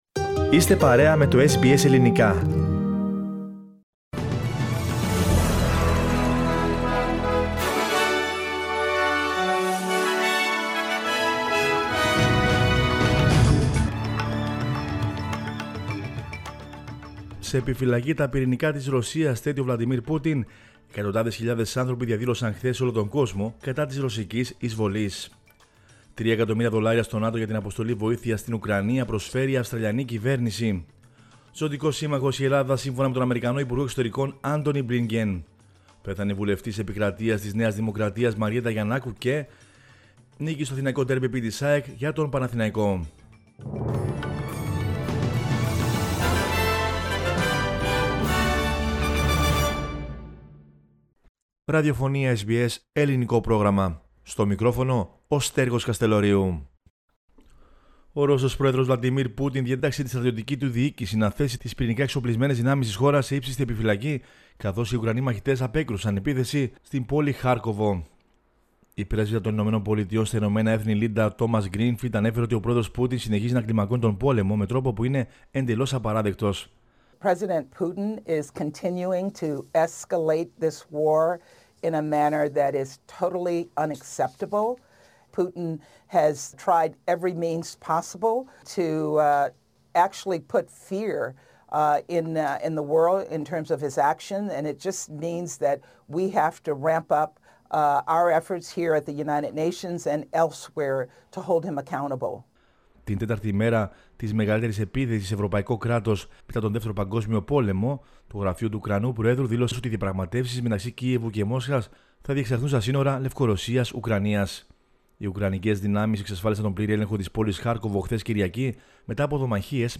News in Greek from Australia, Greece, Cyprus and the world is the news bulletin of Monday 28 February 2022.